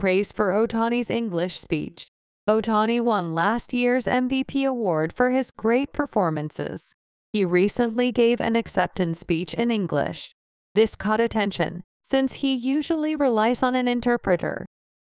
【速度：ややスロー】↑